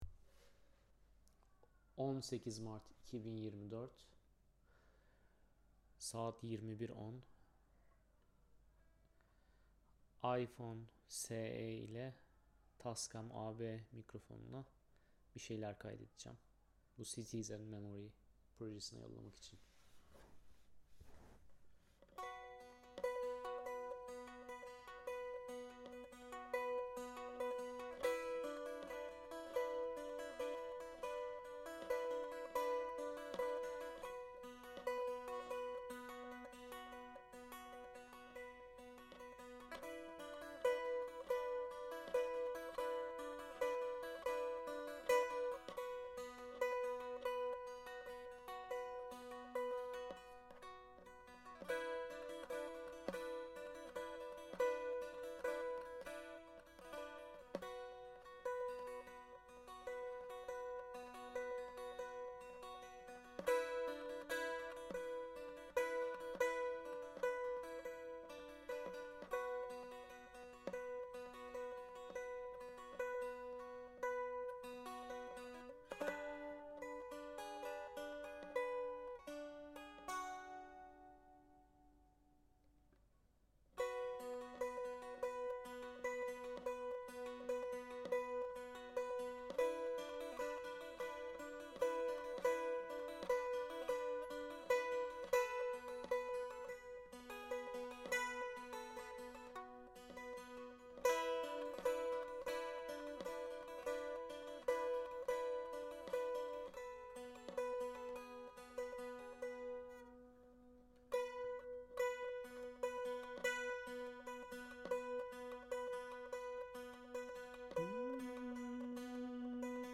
Cura saz
This untouched recording is my response, to a hard day, as I was trying to soothe and calm myself with a musical instrument - a cura bağlama (or cura saz) - from my home country, Türkiye.
I was so tired to move away from my couch, my head was aching, and my fingers were moving across the fret board while this tune had emerged. The recording was done in an intimate setting, in my living room in Ljubljana.